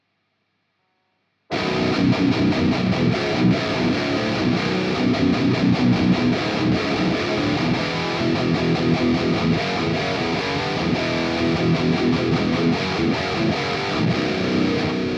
Hier mal zwei Gitarren Tracks einmal Cubase einmal Pro Tools...
Ich sage mal was ich höre- Cubase klingt gut aber etwas mehr digital- lirgendwie in den Höhen anders.